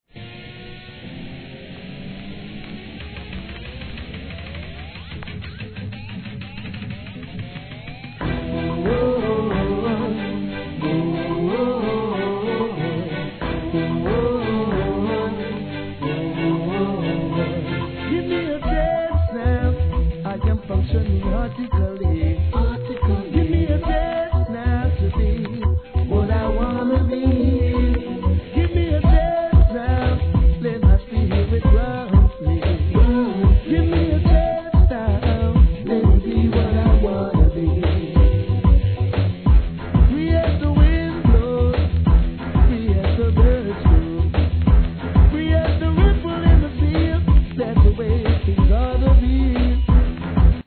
REGGAE
とにかくもう一度チャンスをくれとこぶしを効かせて歌います。